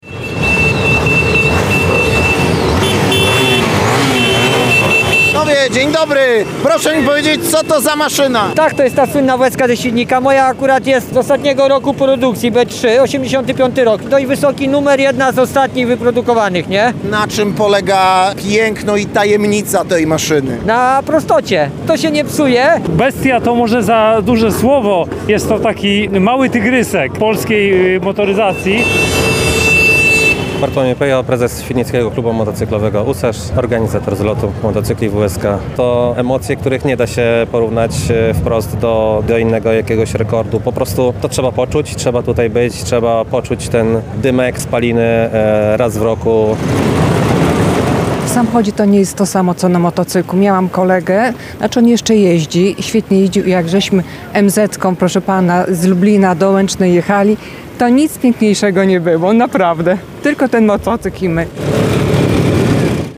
Ulice Świdnika po raz kolejny opanowały kultowe „wueski” – w mieście trwa 17. Ogólnopolski Zlot Motocykli WSK i Innych. Przez niemal 30 lat produkowano je w Świdniku i stały się niemalże ikoną wśród miłośników dawnej motoryzacji.
– Bestia to może za duże słowo, jest to taki mały tygrysek polskiej motoryzacji – dodaje kolejny uczestnik.